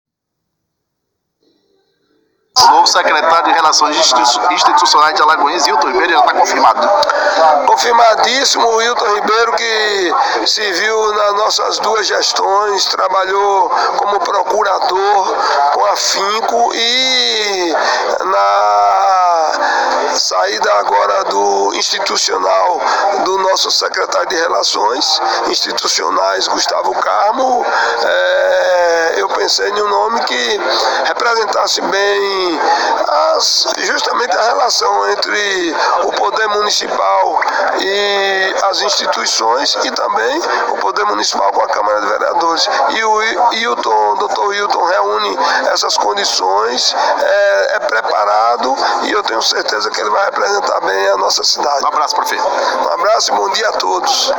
Ouça na integra a declaração do prefeito de Alagoinhas, Joaquim Neto sobre Hilton Ribeiro, no áudio abaixo: